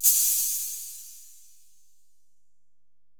808LP42CHH.wav